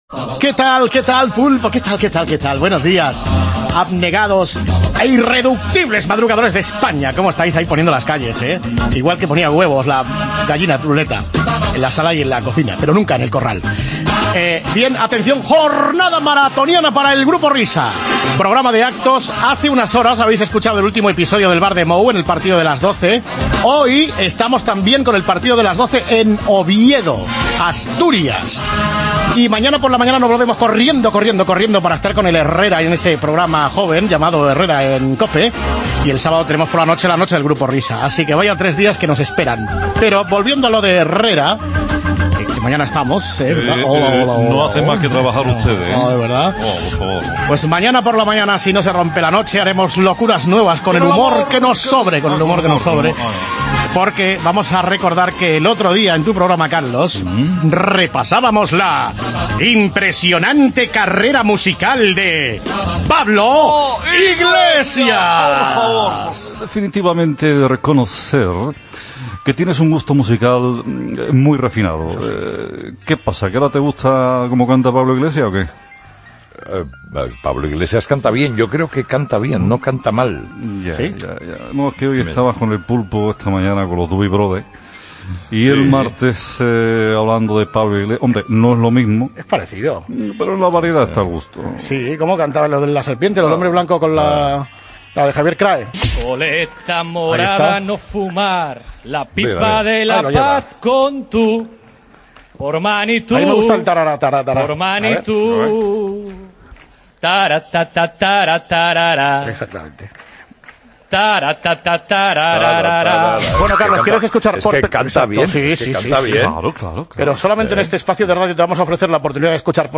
Pablo Iglesias se parte con el rap y el dueto con Carlos Herrera